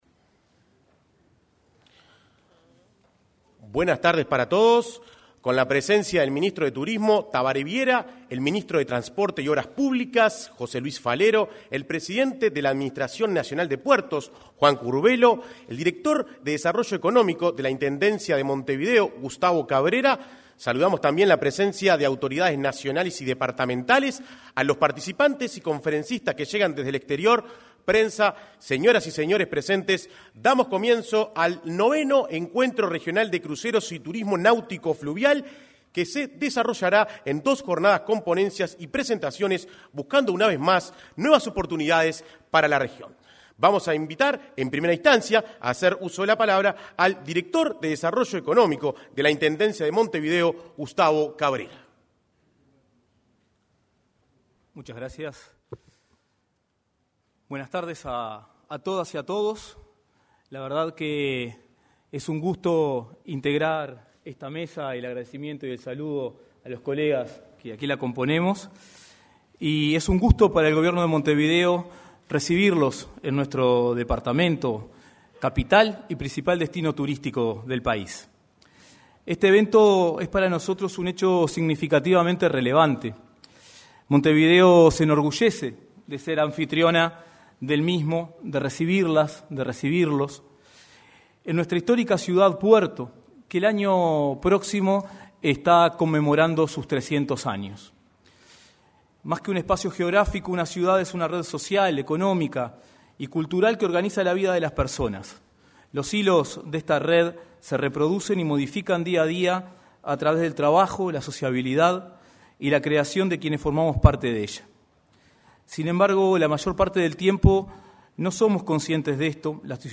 Este jueves 17, con motivo del encuentro internacional de cruceros fluviales se expresaron el presidente de la Administración Nacional de Puertos,